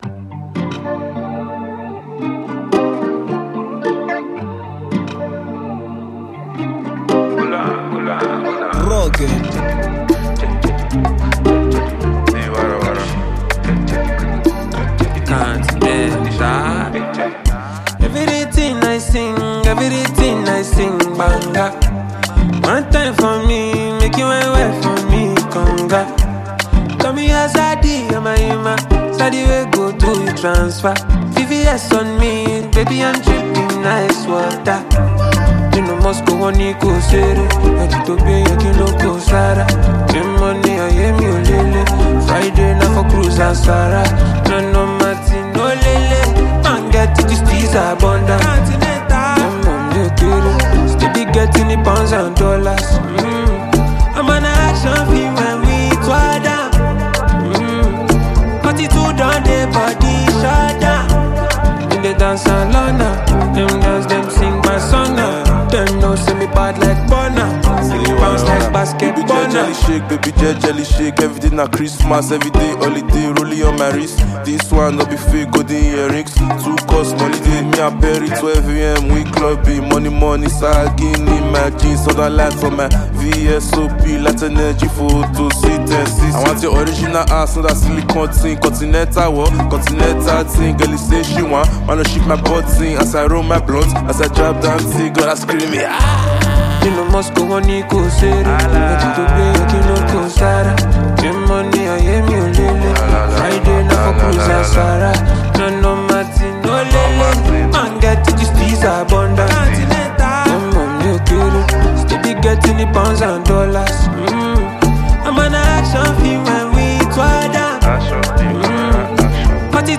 Versatile fast-rising Ghanaian singer
enchanting jam